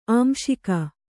♪ āmśika